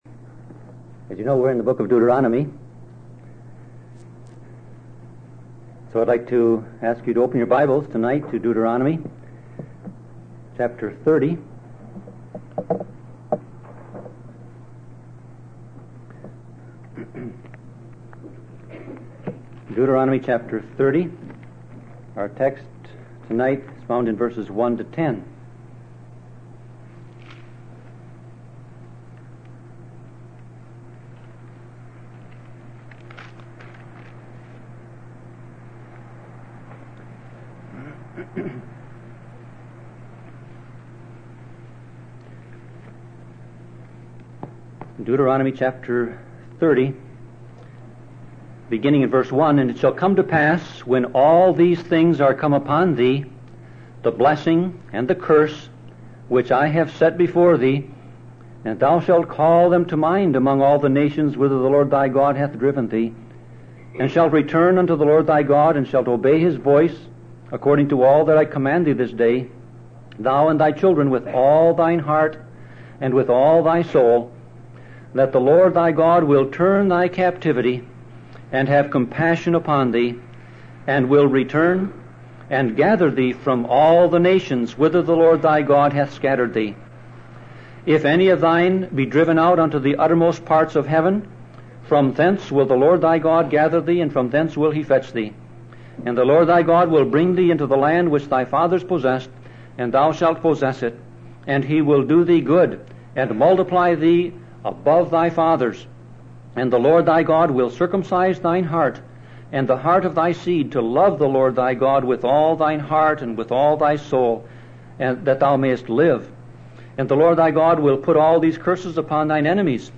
Sermon Audio Passage